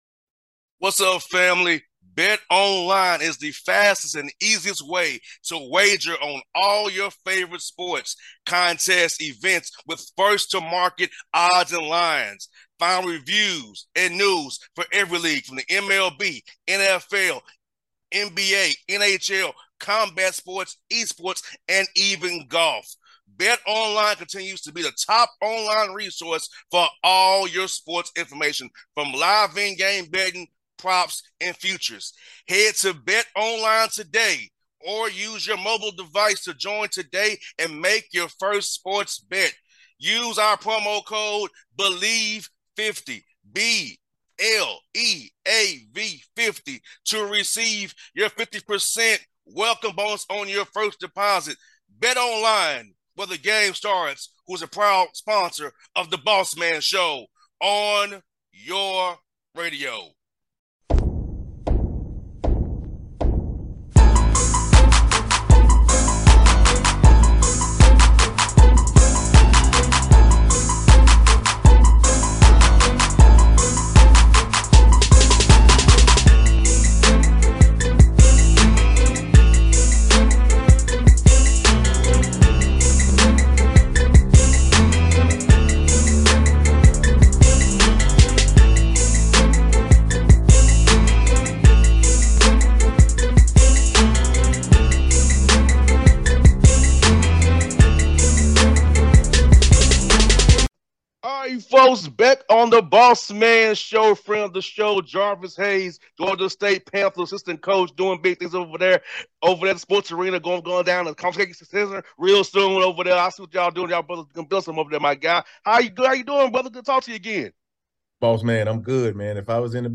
Jarvis Hayes Interview